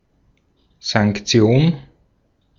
Ääntäminen
Synonyymit beating castigation chastisement punition Ääntäminen US : IPA : ['pʌn.ɪʃ.mənt] Haettu sana löytyi näillä lähdekielillä: englanti Käännös Ääninäyte Substantiivit 1.